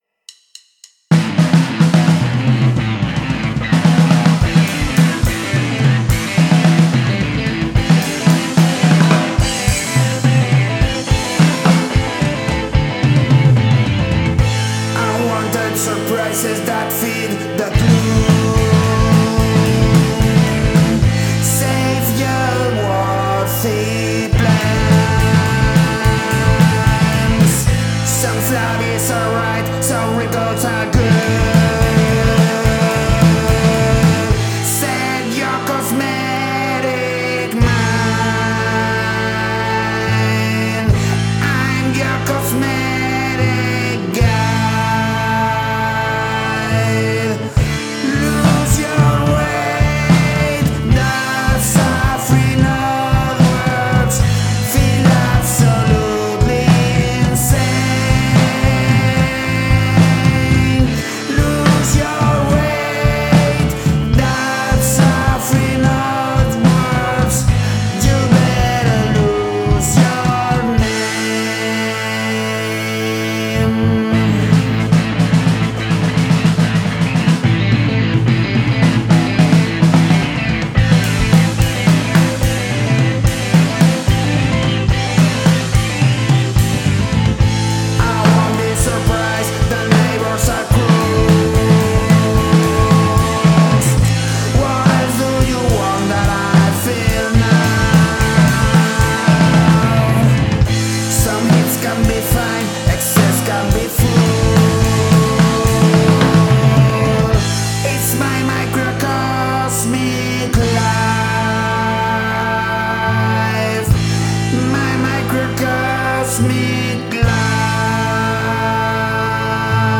It's frenetic and tense.